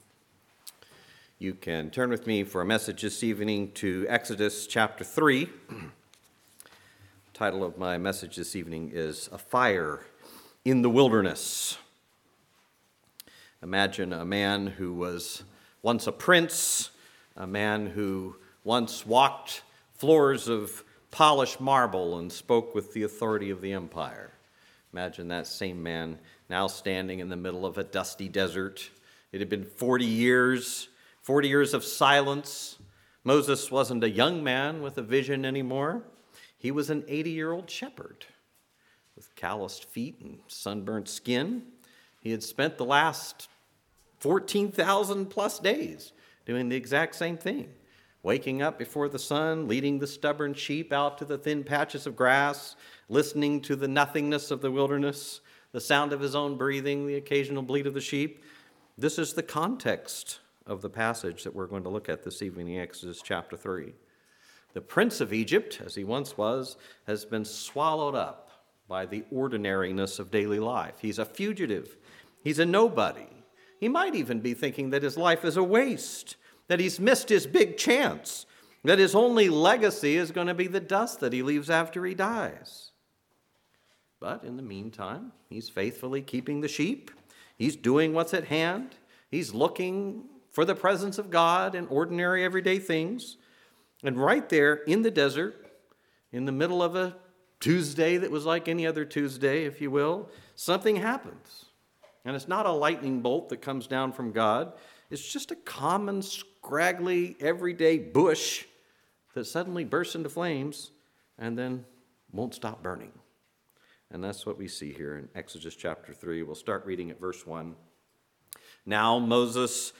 Sermons
Susquehanna Valley | Revivals 2026